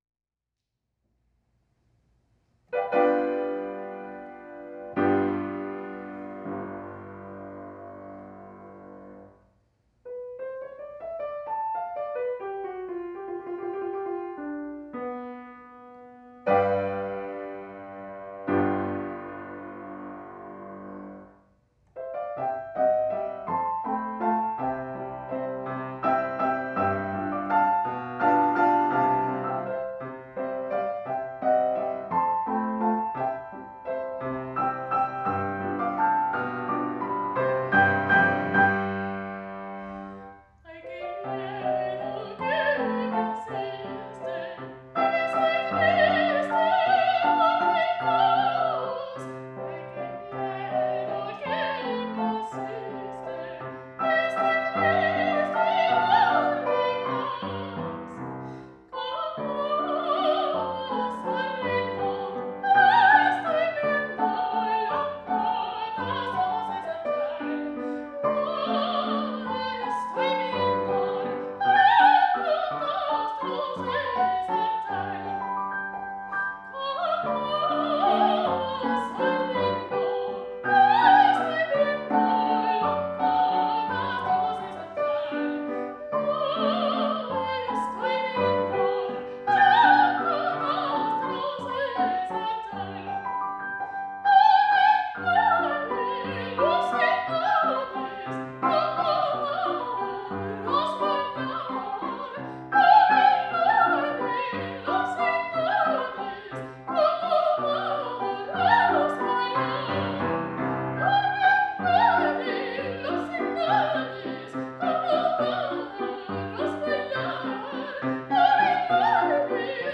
MÚSICA ECUATORIANA